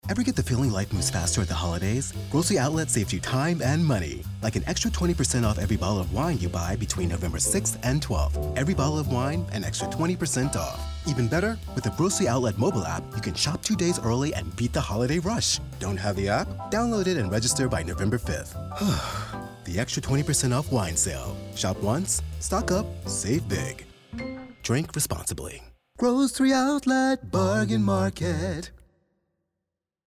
Commercial Work
Studio: Sennheiser Microphone